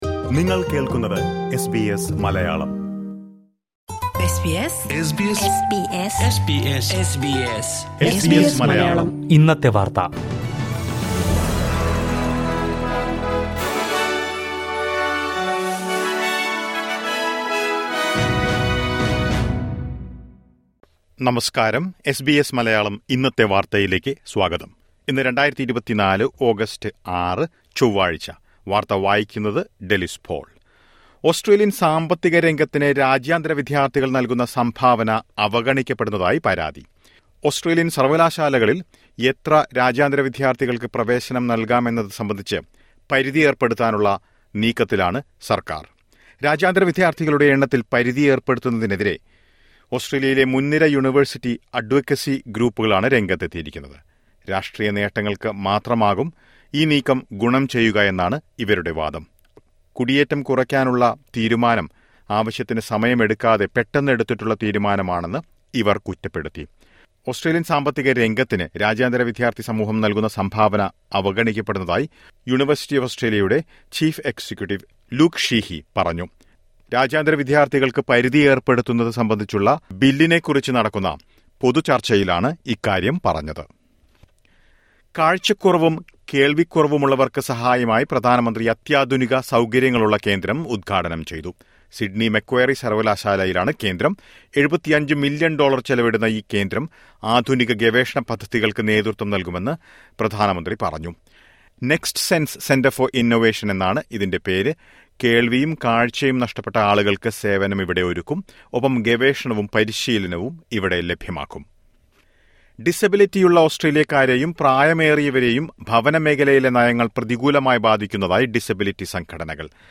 2024 ഓഗസ്റ്റ് ആറിലെ ഓസ്‌ട്രേലിയയിലെ ഏറ്റവും പ്രധാന വാര്‍ത്തകള്‍ കേള്‍ക്കാം...